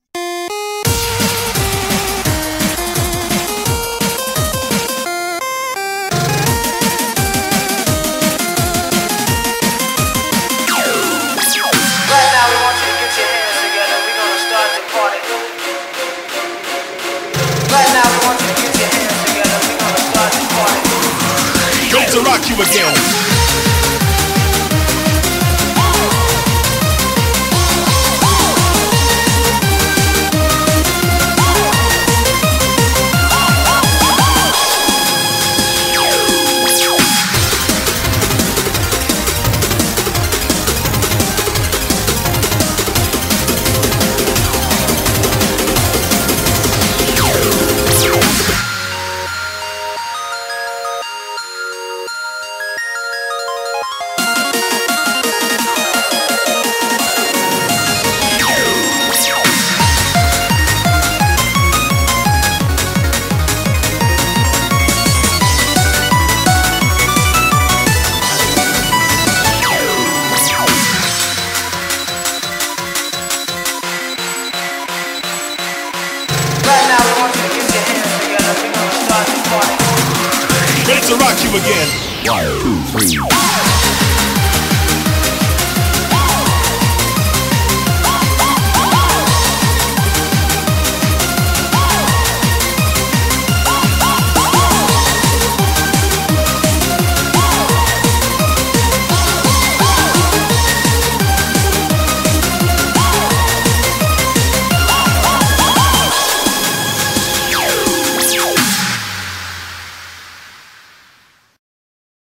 BPM171
Audio QualityPerfect (Low Quality)